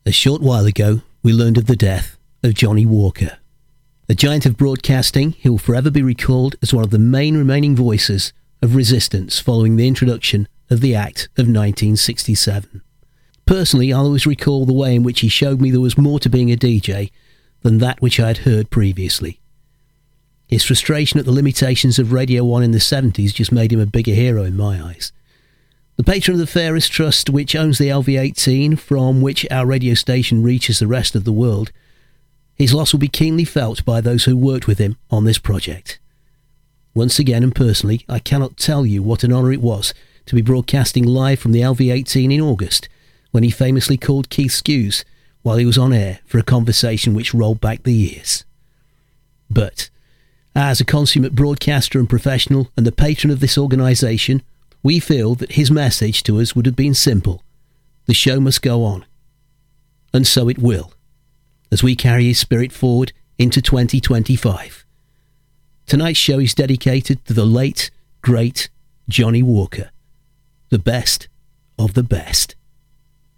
We broadcast the following obituary at 2200 & 2300 on New Years Eve.
Johnnie-Walker-Obit-piece-upfront-of-show.mp3